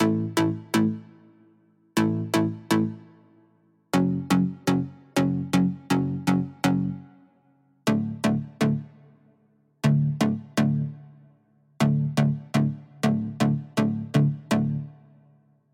电子舞蹈节拍
Tag: 打击乐器 hi trap 打击乐循环 低音的 铜基合金 舞蹈 帽子 循环 小军鼓 节奏循环 节拍 电子 绝妙 敲打声